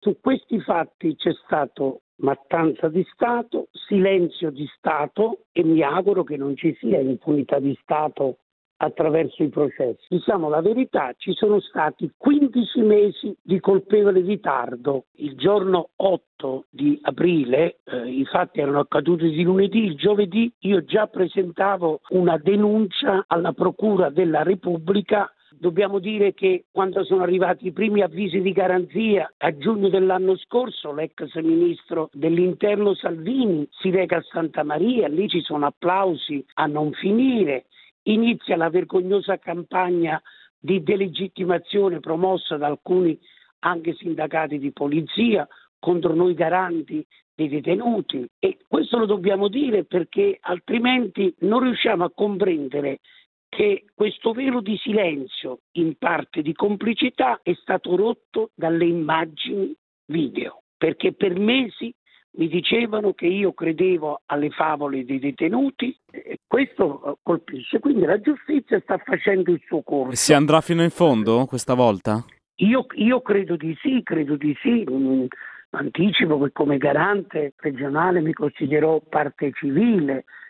Sentiamo Ciambriello ai nostri microfoni: